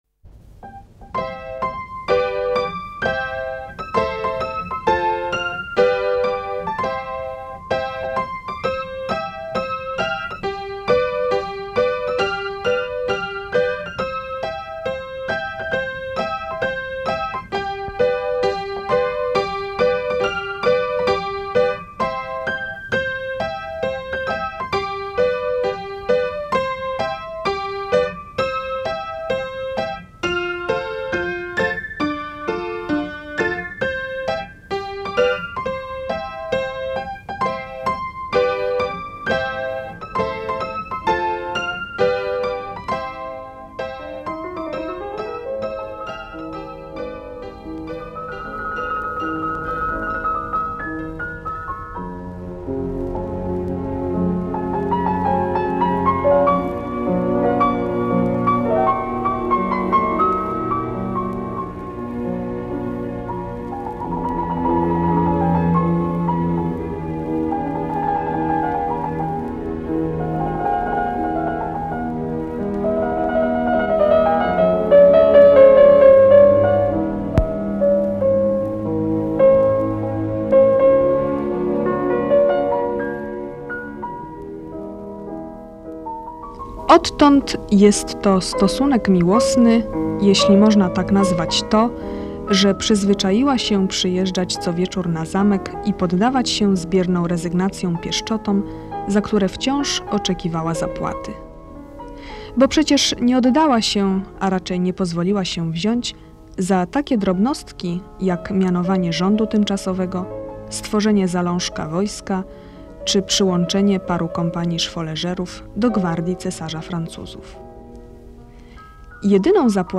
Słuchowisko na podstawie powieści